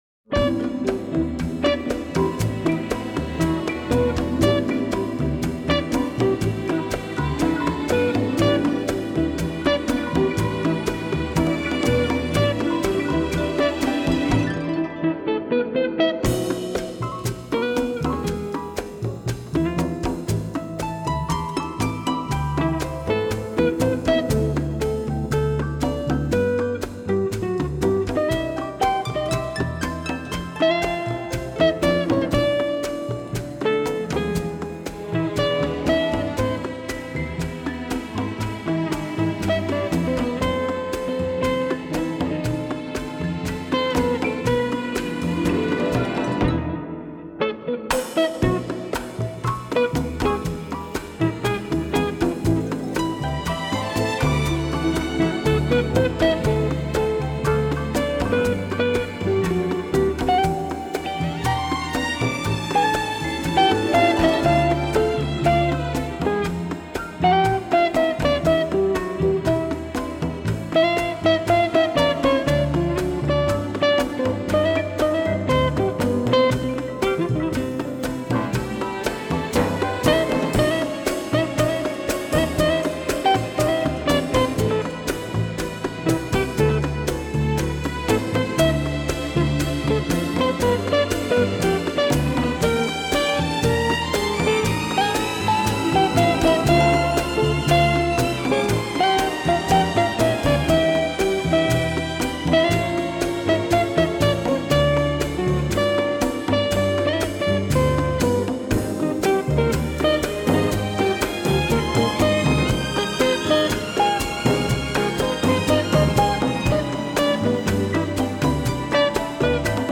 Genre:Instrumental